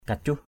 kacuh.mp3